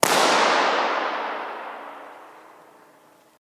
Wow! This room is big, all the surfaces are parallel and hard, there’s very little to break up the sound… You can almost imagine what it sounds like.
Here’s a balloon pop.
What you’re hearing is about 3.5 seconds of reverberation after the balloon pop.
yokumtown-before.mp3